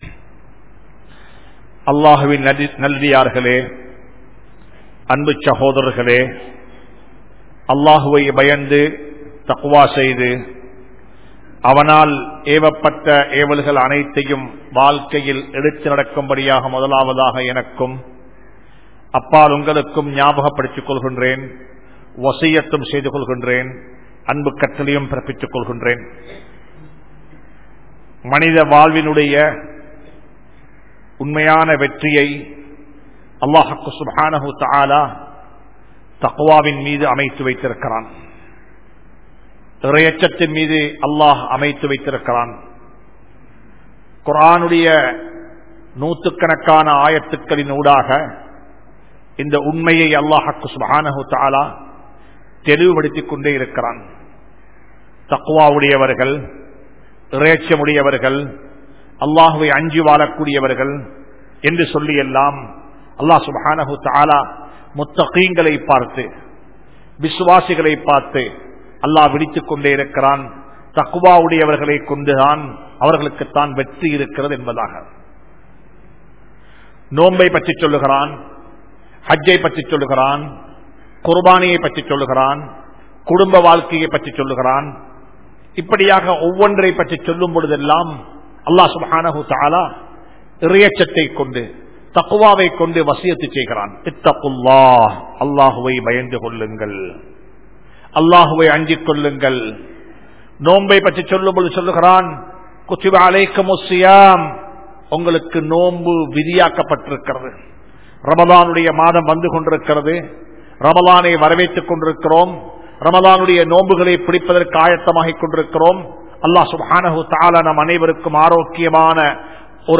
Amaanitham | Audio Bayans | All Ceylon Muslim Youth Community | Addalaichenai